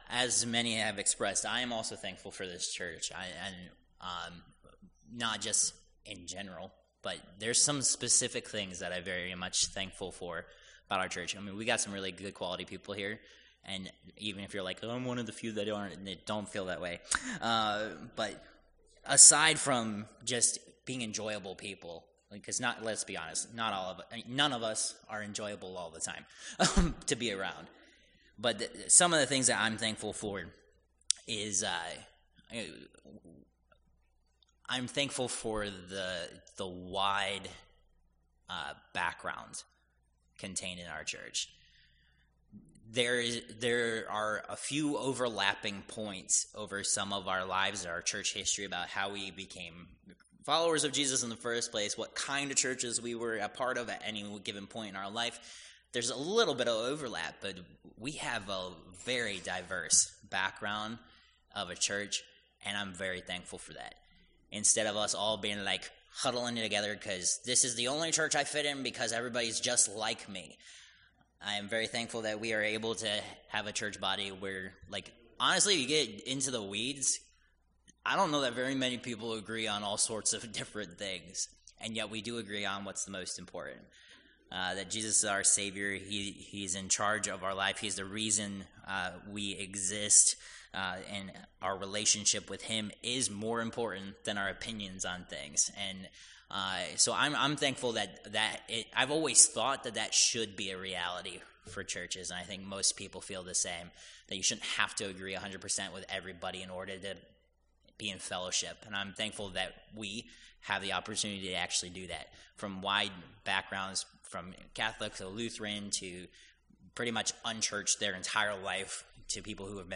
Service Type: Worship Service Topics: Thanksgiving